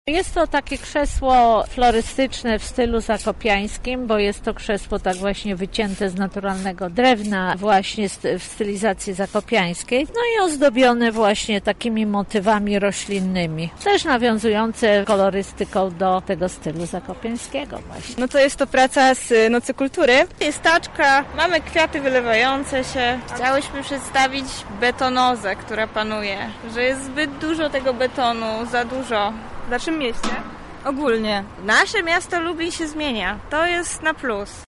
Z twórcami rozmawiała nasza reporterka:
sonda